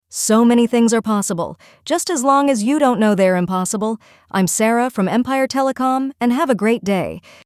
Empire Telecom offers its customers free, professionally recorded auto-attendant greetings and voicemail messages.
Female